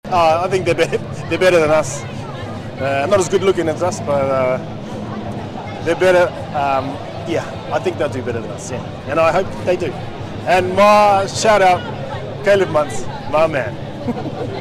In an exclusive interview with FBC Sports, Little says the team has what it takes to deliver on the sport’s biggest stage.